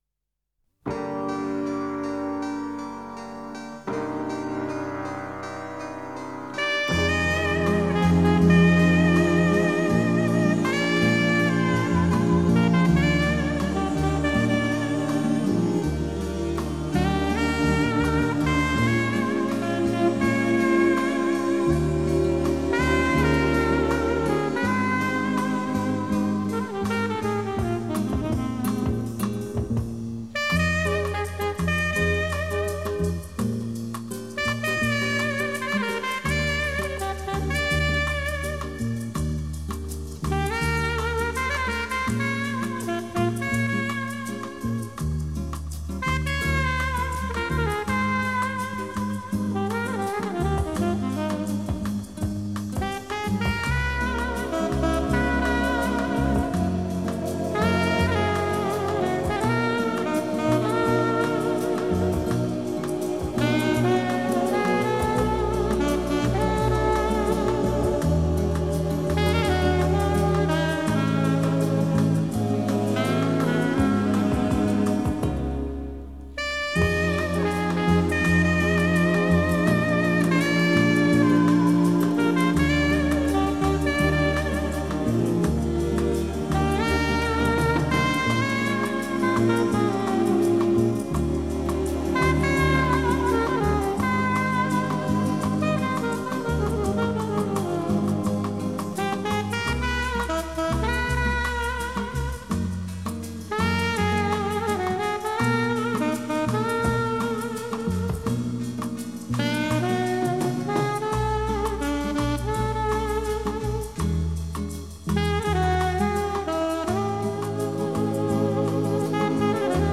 Жанр: Jazz, Easy Listening